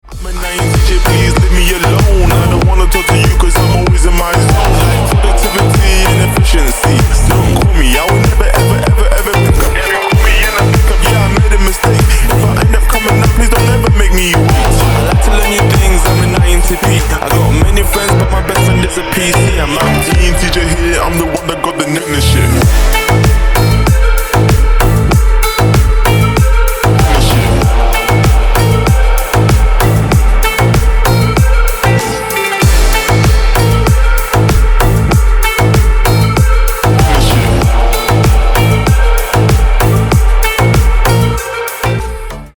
• Качество: 320, Stereo
качающие
Стиль: slap house